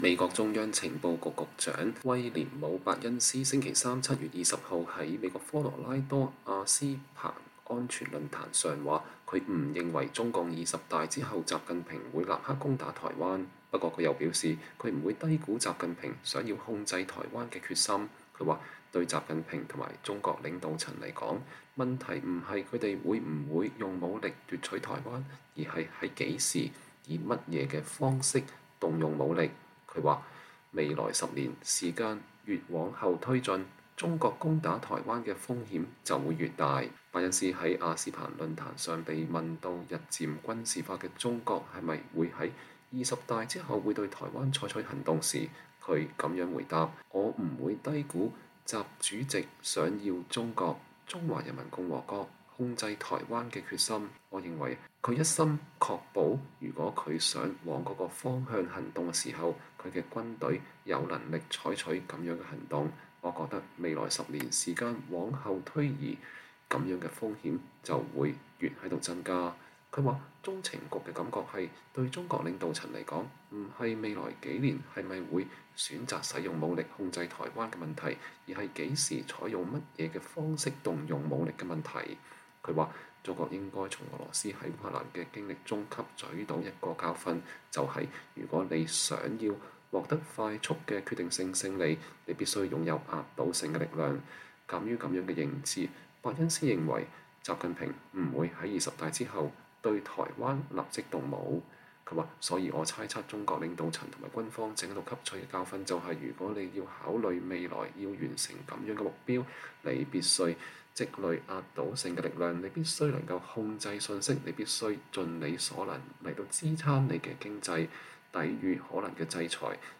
美國中央情報局局長威廉姆·伯恩斯（William Burns）星期三（7月20日）在美國科羅拉多阿斯彭安全論壇上說，他不認為中共“二十大後”習近平會立刻攻打台灣。